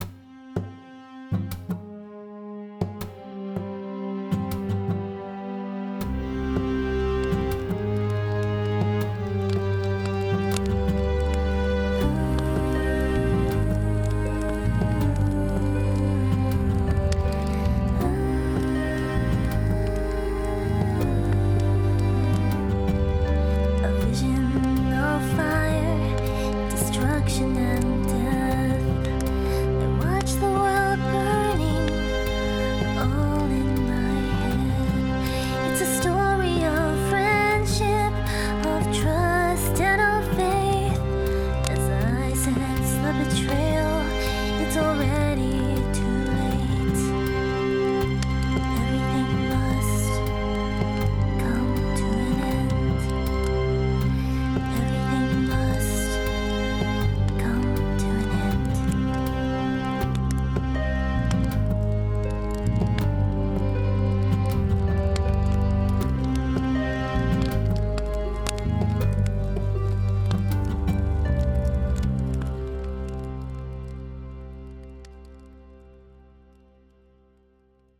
a-vision-of-fire-orchestral.ogg